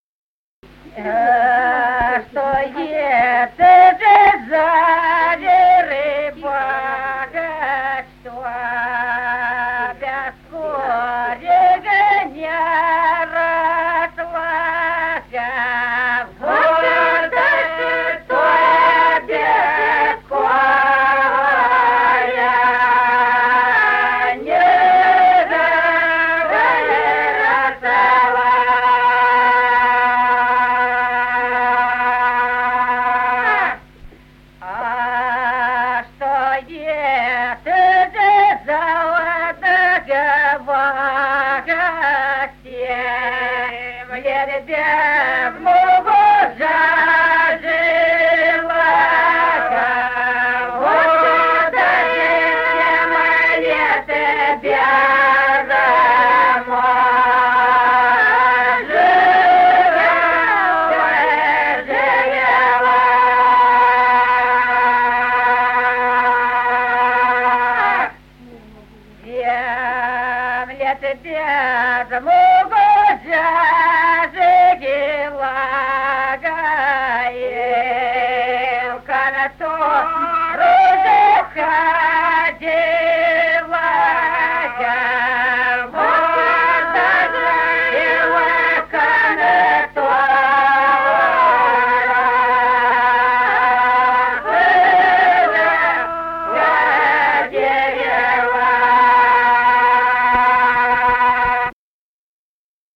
Песни села Остроглядово А что ето ж за верба.
Песни села Остроглядово в записях 1950-х годов